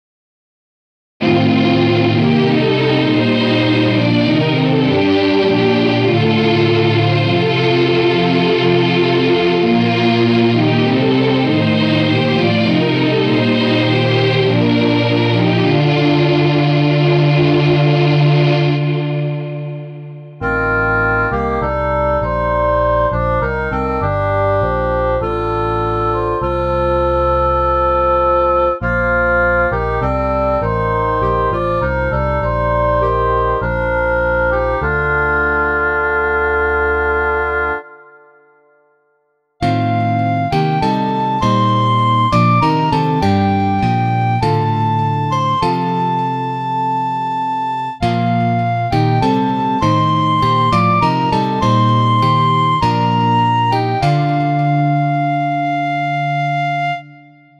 Chor, 4-stimmig, B-Dur
Choirs, 4 voices B major
jl2020-chor.wav